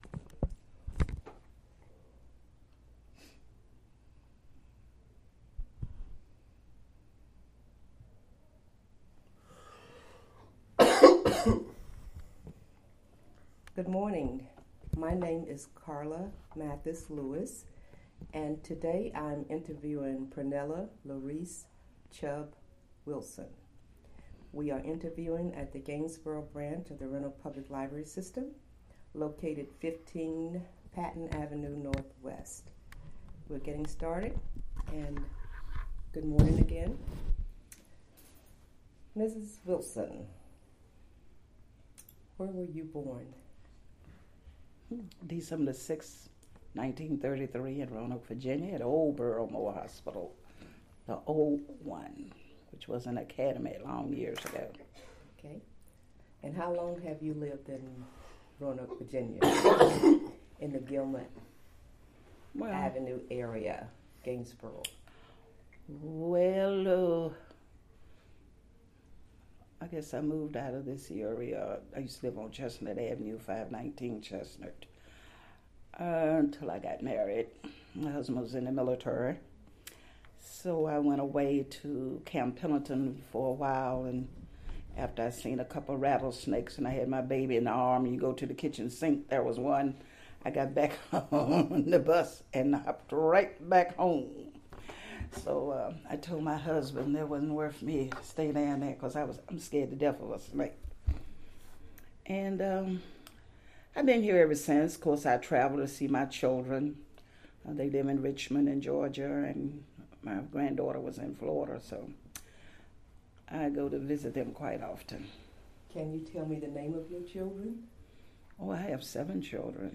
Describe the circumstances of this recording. Location: Gainsboro Branch Library